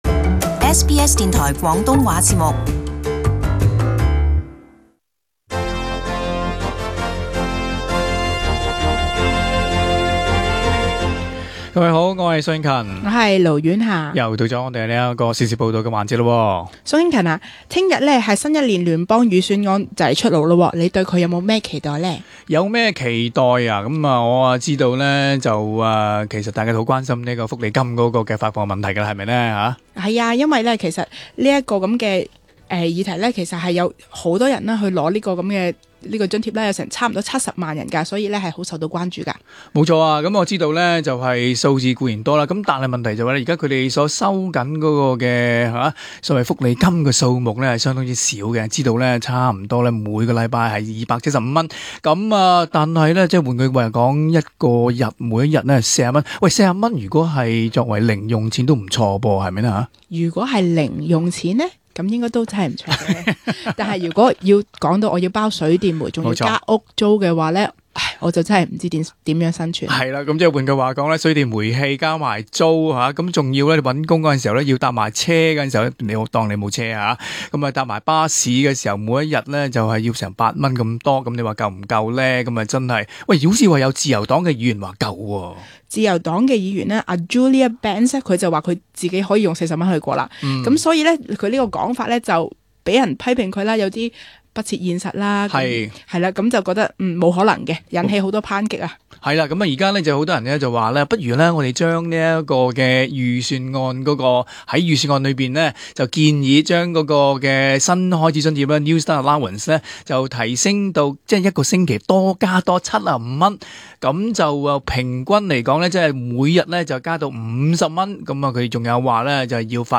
【時事報導】新開始津貼會否提高惹關注